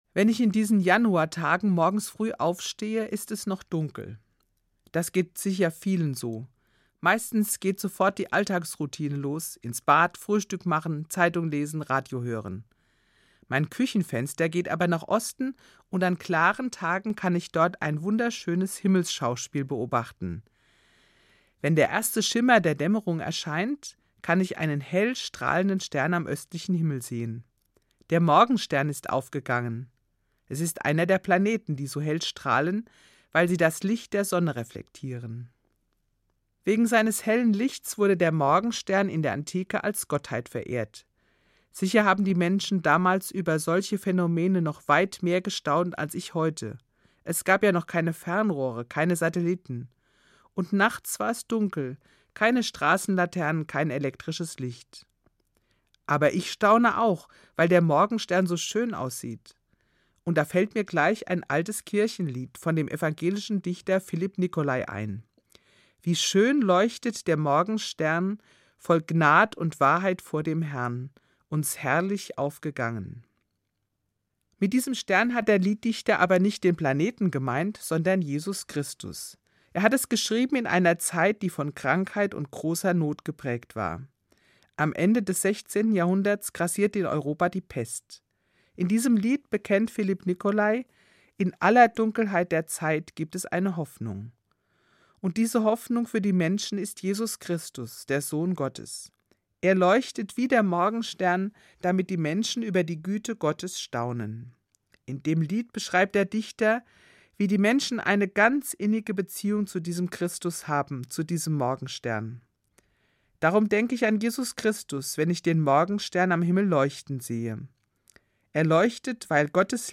Katholische Gemeindereferentin im Ruhestand